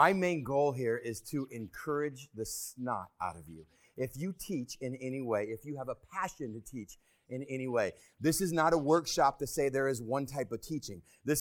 The sample file is a small excerpt from a conference I was volunteering at.
The following sample is the same clip after the noise reduction filter was applied.  It is still not perfect but way more intelligible than before.
noise-reduiced.m4a